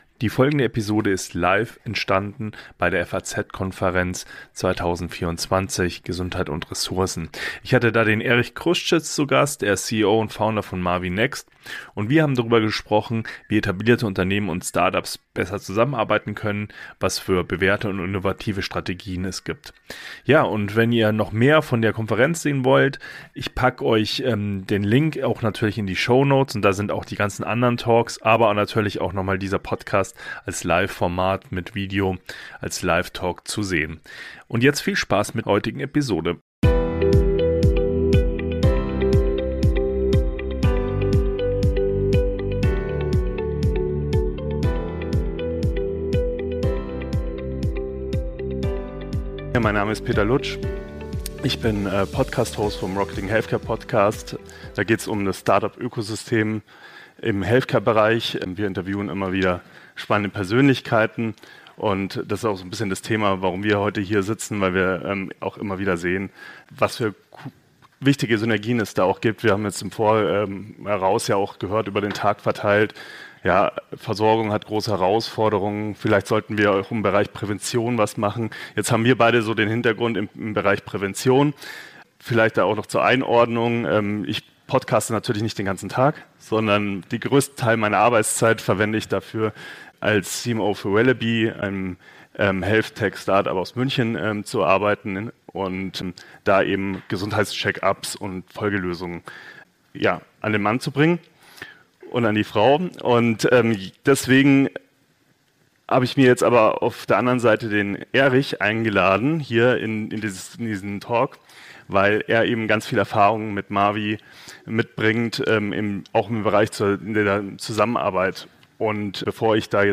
Live Talk